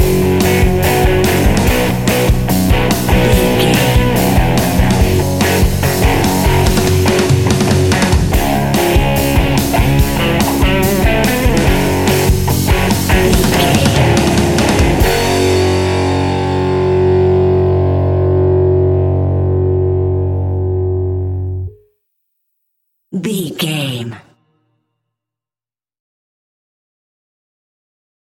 Epic / Action
Fast paced
Ionian/Major
F#
hard rock
blues rock
distortion
rock guitars
Rock Bass
Rock Drums
heavy drums
distorted guitars
hammond organ